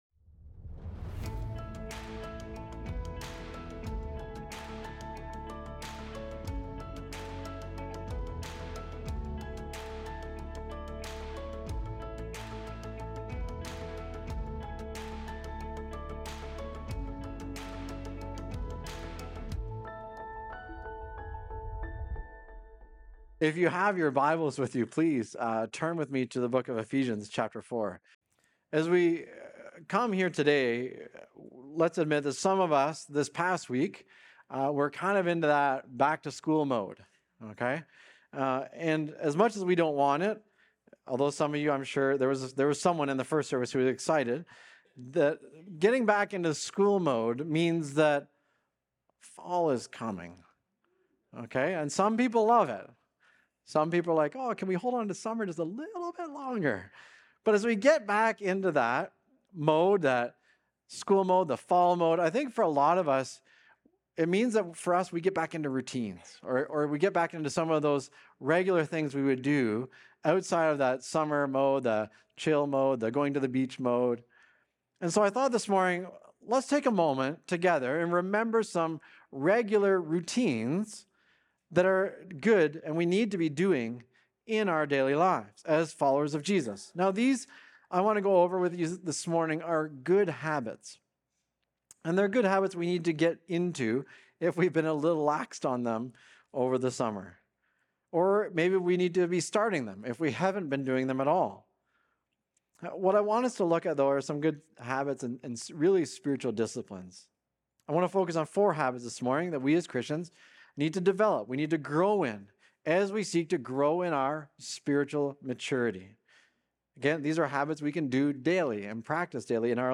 Recorded Sunday, September 7, 2025, at Trentside Fenelon Falls.